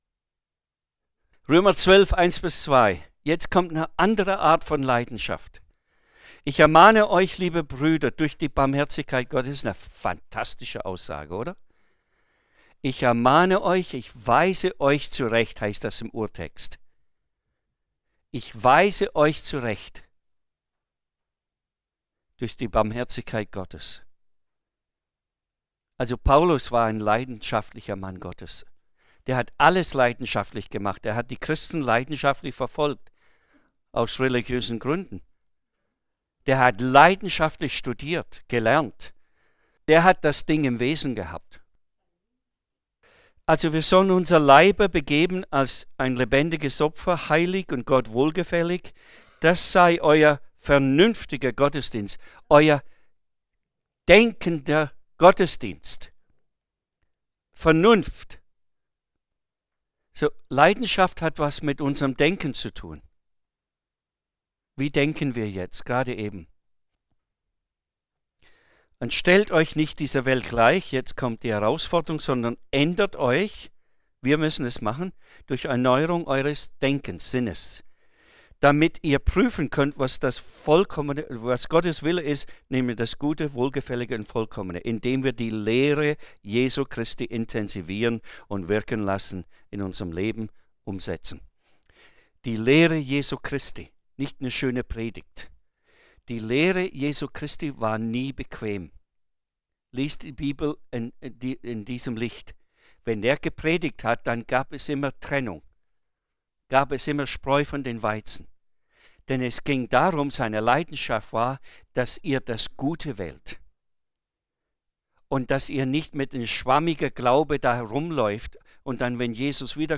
Referent